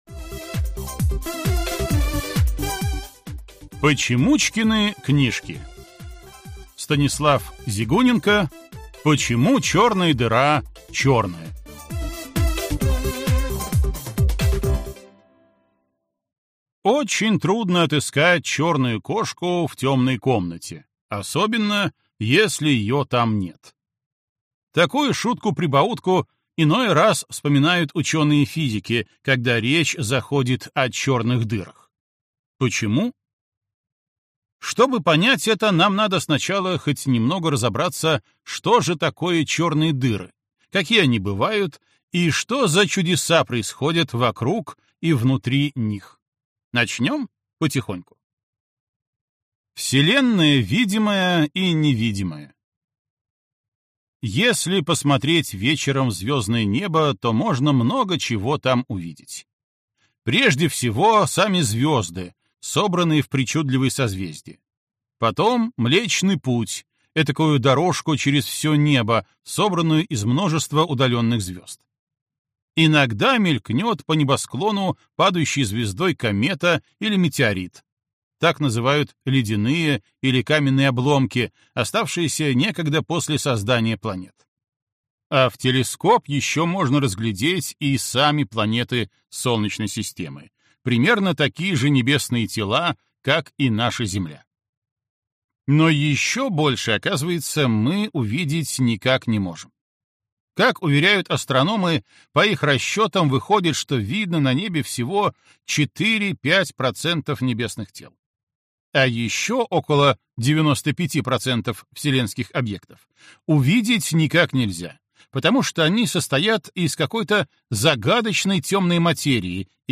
Аудиокнига Почему чёрная дыра чёрная?